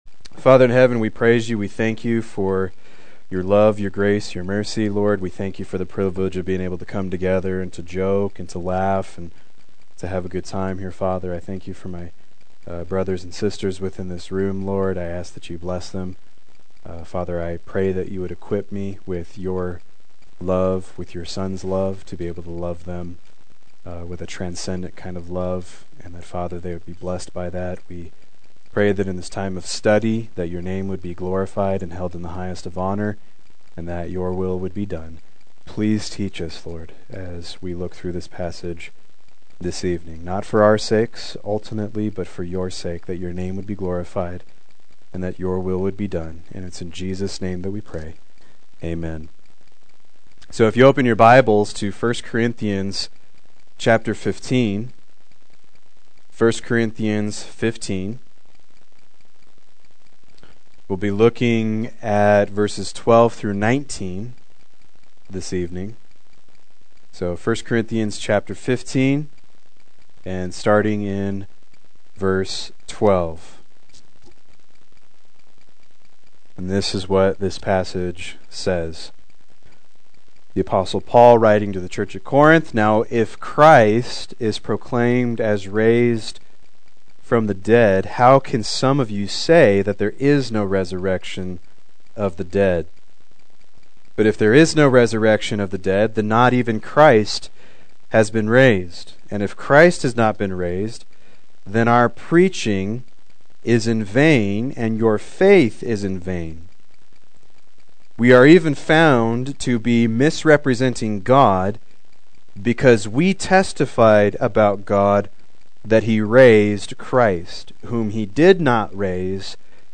Proclaim Youth Ministry - 11/04/16
Play Sermon Get HCF Teaching Automatically.